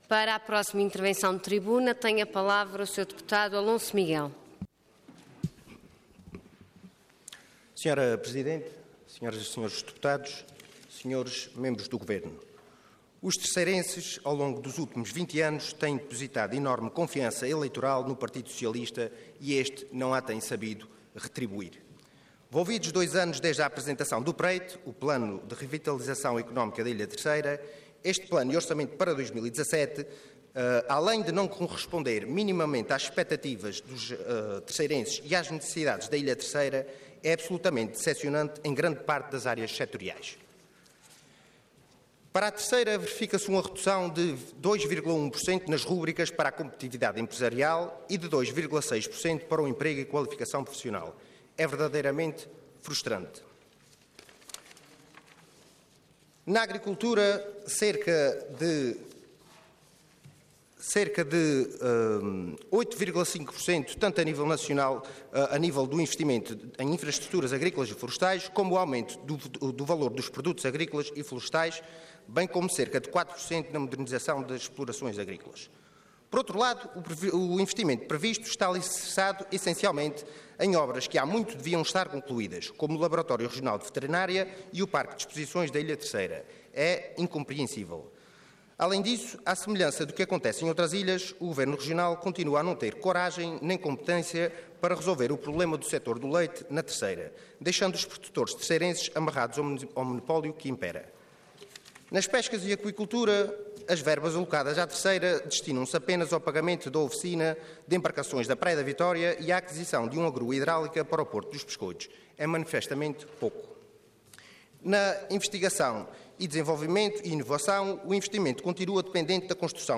Intervenção Intervenção de Tribuna Orador Alonso Miguel Cargo Deputado Entidade CDS-PP